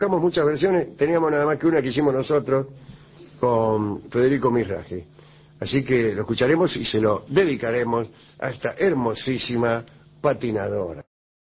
El líder de Alianza Nacional, Jorge Larrañaga, fue entrevistado en el programa de Emiliano Cotelo, En Perspectiva, donde se refirió a las nuevas etapas de la política uruguaya y a posibles posturas en caso del triunfo del Partido Nacional en las próximas elecciones.